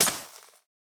Minecraft Version Minecraft Version 1.21.5 Latest Release | Latest Snapshot 1.21.5 / assets / minecraft / sounds / block / suspicious_sand / break1.ogg Compare With Compare With Latest Release | Latest Snapshot